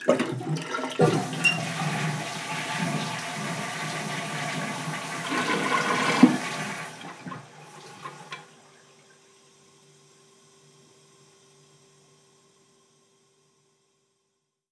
Cisterna de wáter 3
cisterna
Sonidos: Agua
Sonidos: Hogar